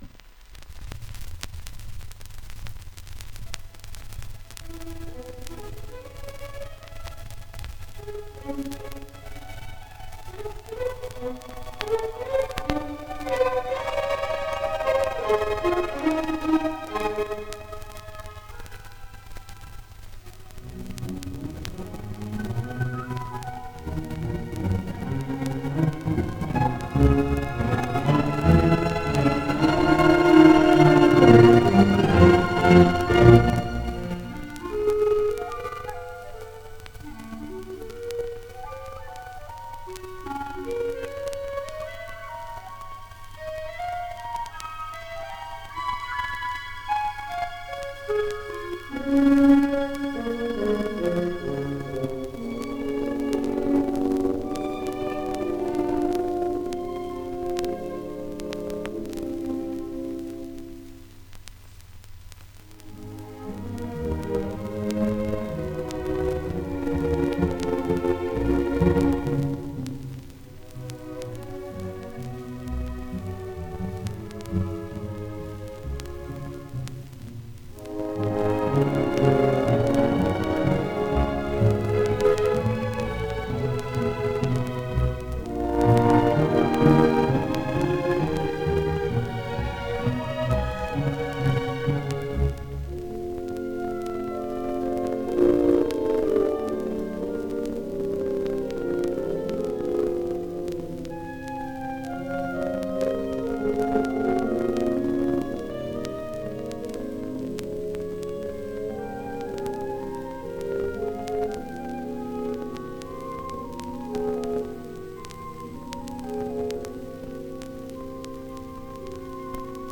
discos : 78 rpm ; 30 cm